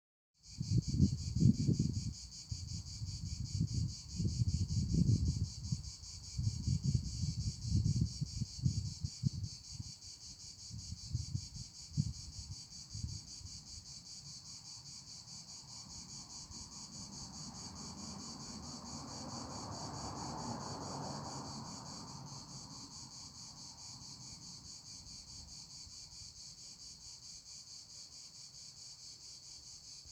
Cigale noire Cicadatra atra